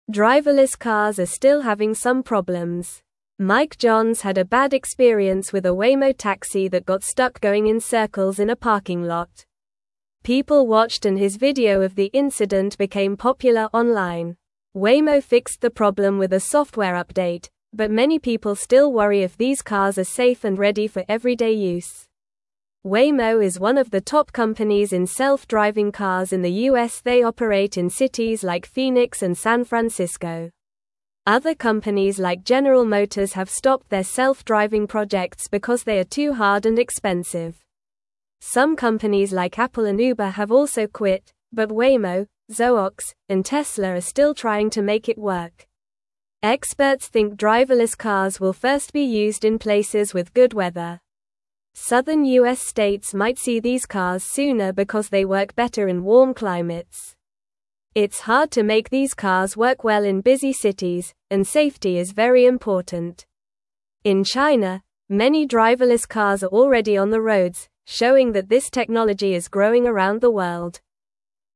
Normal
English-Newsroom-Lower-Intermediate-NORMAL-Reading-Driverless-Cars-Safe-or-Not-for-Everyone.mp3